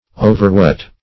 Search Result for " overwet" : The Collaborative International Dictionary of English v.0.48: Overwet \O"ver*wet\, n. Excessive wetness.